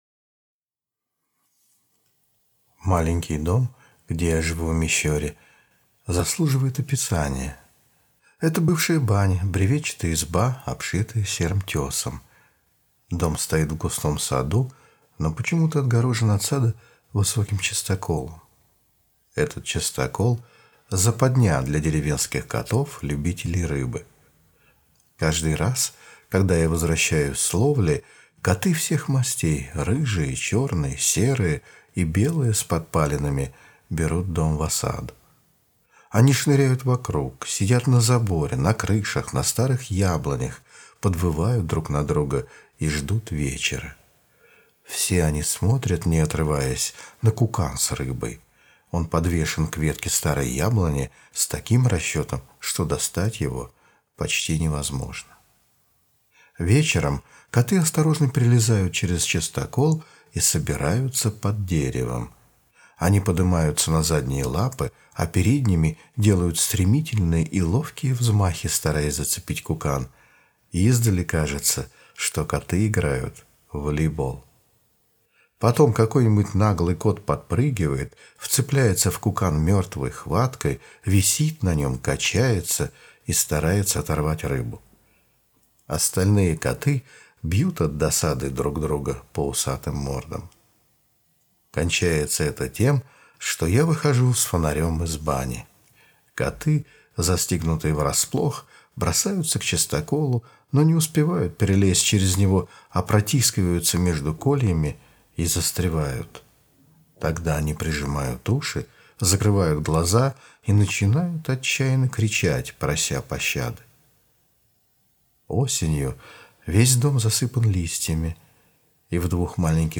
Аудиорассказ «Мой дом»